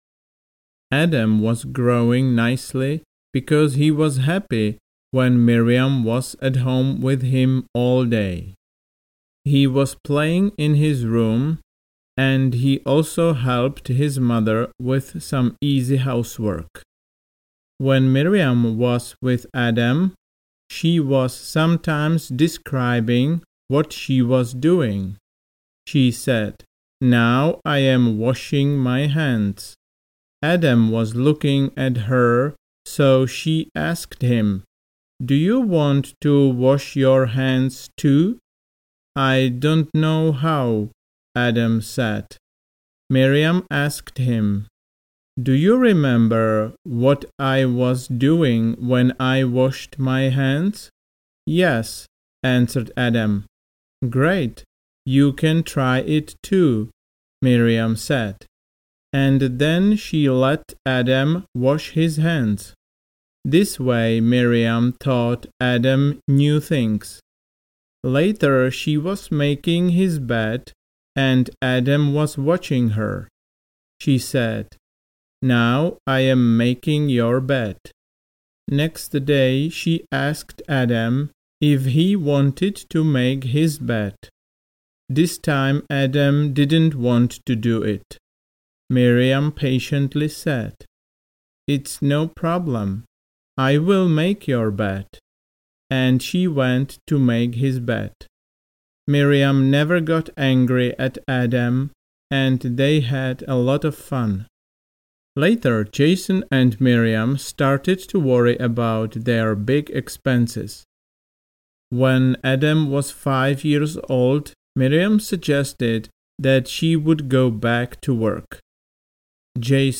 Bad Boy audiokniha
Ukázka z knihy